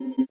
Index of /phonetones/unzipped/Motorola/AURA-R1/slider_tones
ui_basic_open.wav